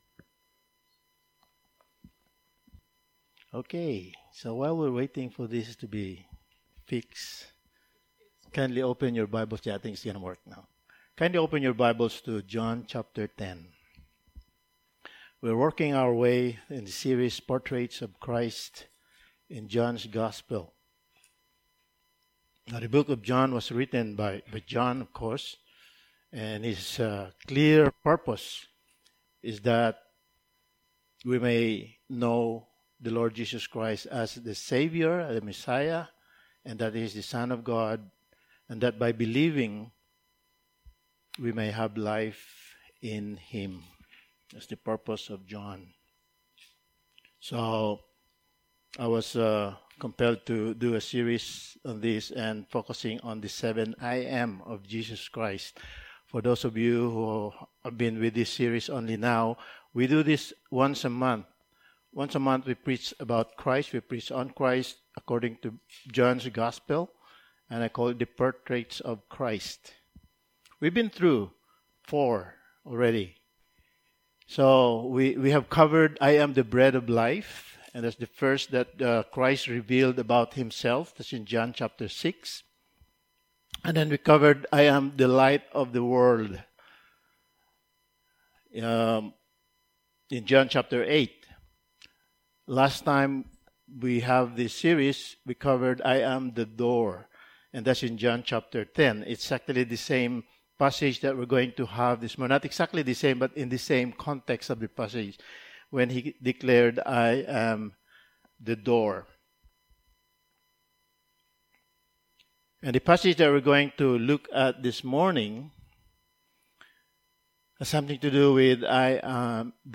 Passage: John 10:9-21 Service Type: Sunday Morning